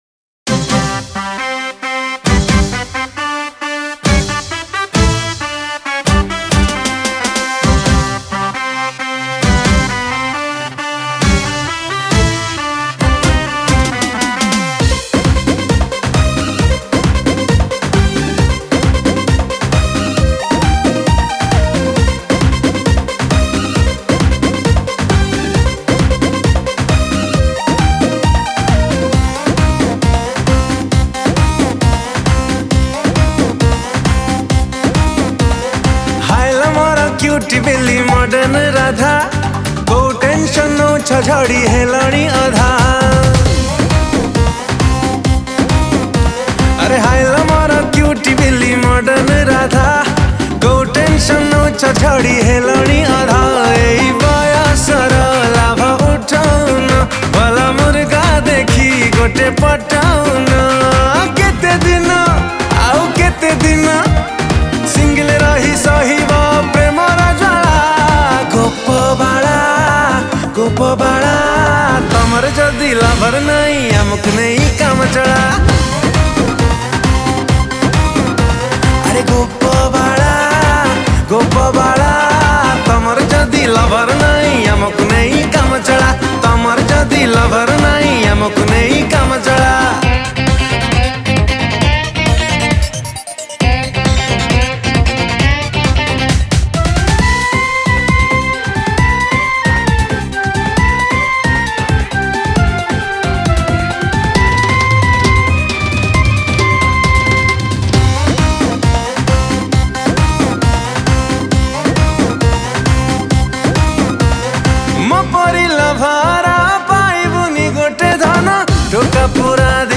Odia Dance Song